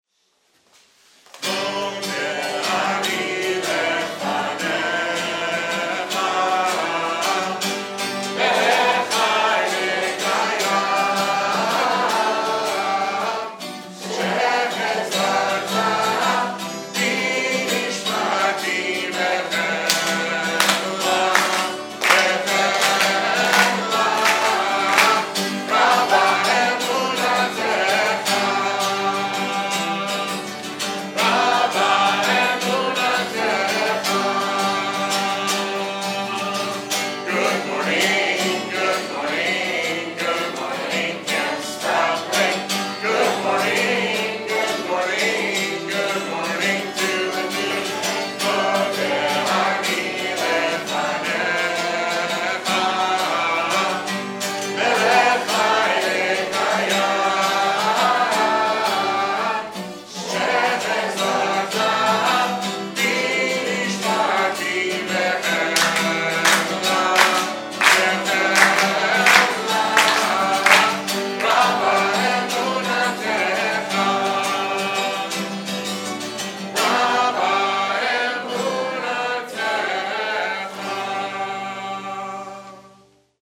With the help of our talented Shira specialists, campers sing during our musical t’fillot services, after meals, and for special performances.